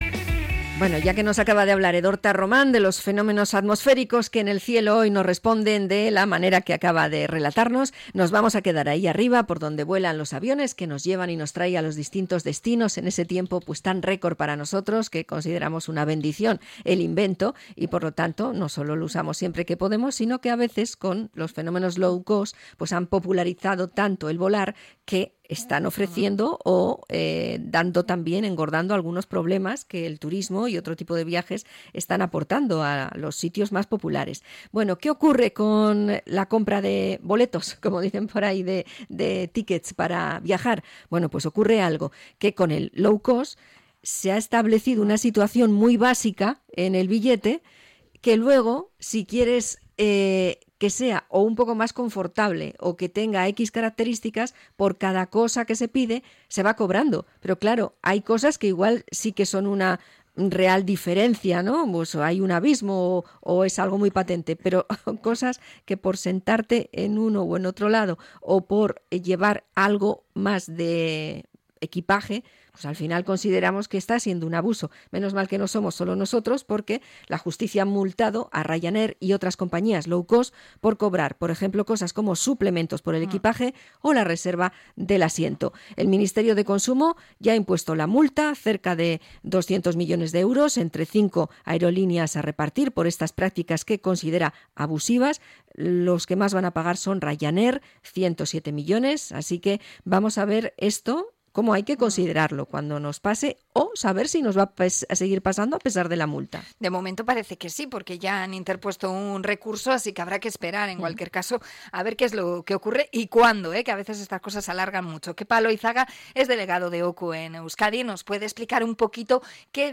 Entrevista a OCU Euskadi por la multa a las aerolíneas